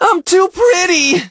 hotshot_die_02.ogg